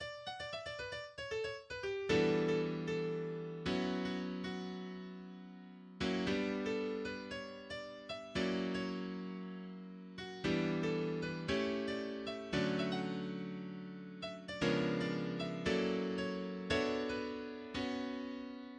instrumental jazz fusion composition